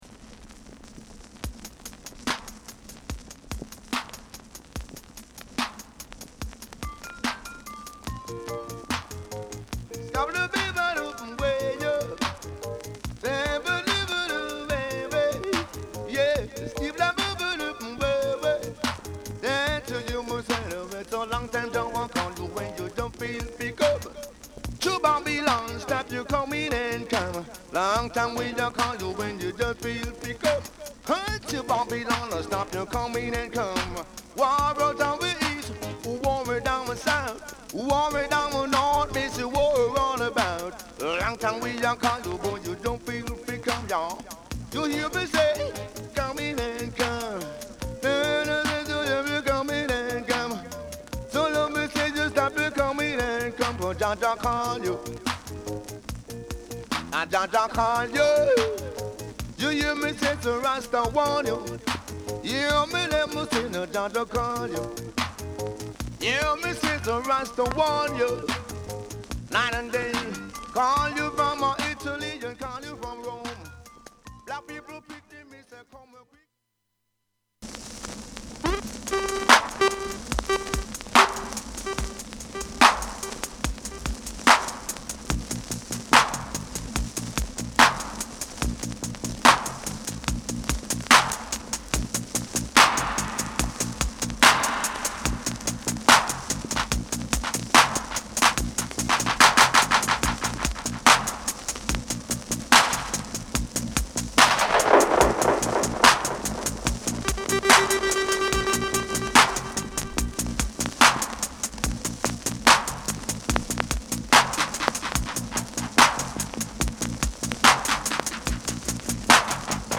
Genre: Roots / Toasting